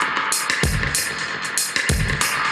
Index of /musicradar/dub-designer-samples/95bpm/Beats
DD_BeatFXA_95-03.wav